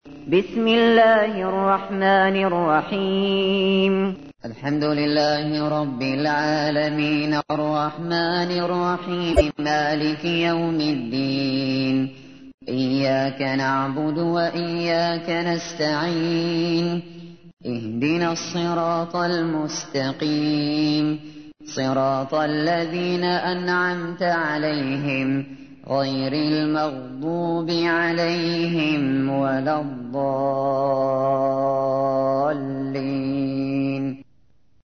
تحميل : 1. سورة الفاتحة / القارئ الشاطري / القرآن الكريم / موقع يا حسين